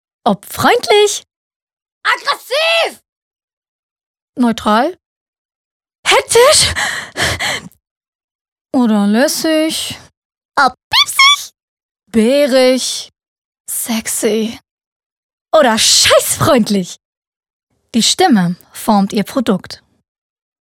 Sprecherin Synchron, Werbung, TV, Kino, Funk, Voice-Over, Höhrbuch, Hörspiel, Online-Games, Native Speaker Deutsch und Türkisch
Sprechprobe: Sonstiges (Muttersprache):